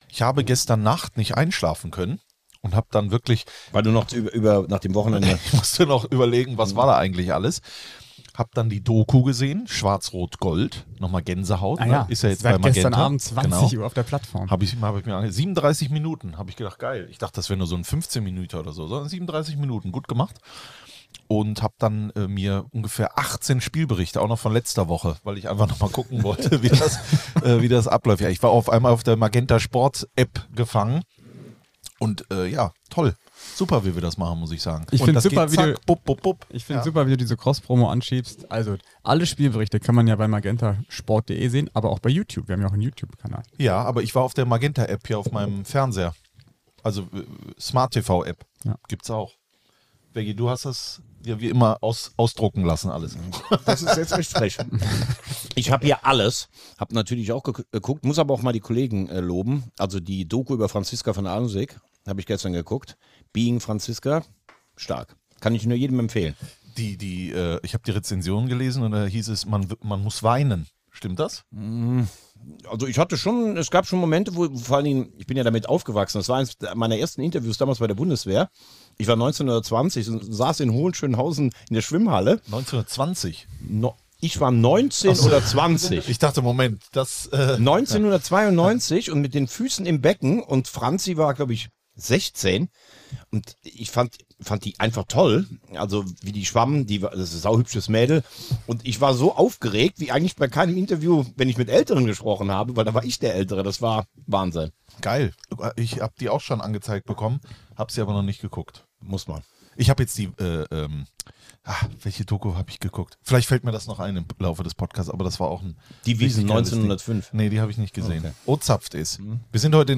In Folge 85 von 4zu3 betritt die Runde zum ersten Mal die Wagner-Studios!